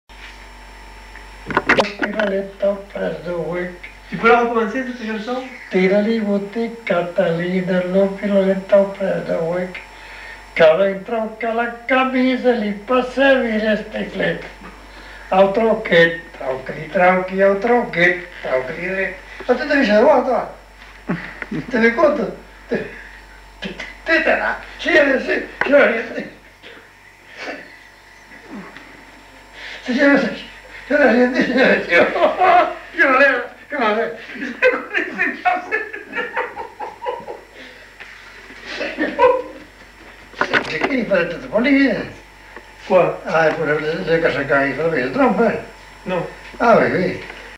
Aire culturelle : Petites-Landes
Genre : chant
Effectif : 1
Type de voix : voix d'homme
Production du son : chanté